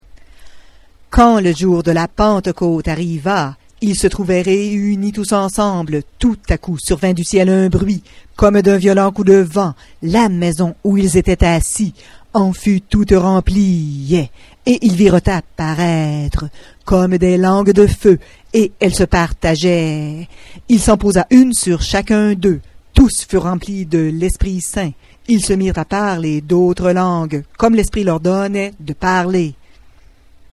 Ac 2, 1-4 en rap
Par contre, la version 'rapée' demeure la même.
6_Ac2-rap.mp3